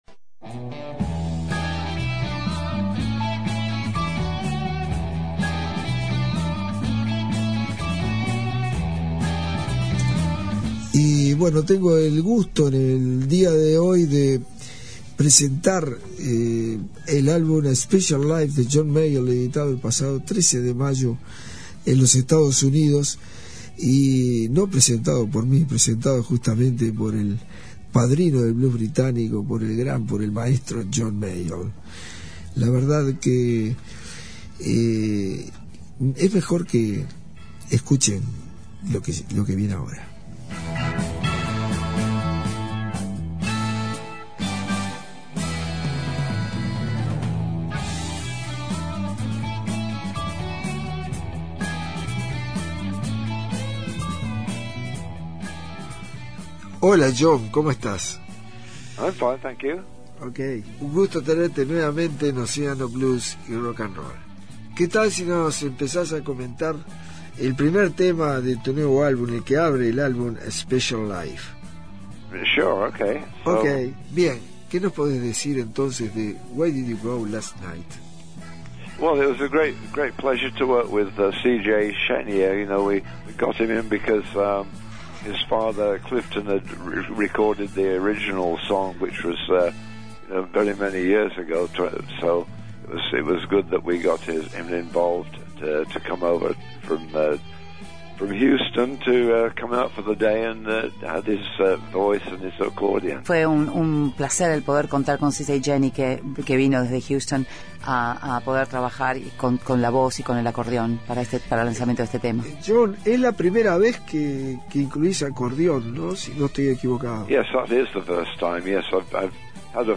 Entrevista a John Mayall - Océano
Reviví la entrevista al legendario blusero John Mayall.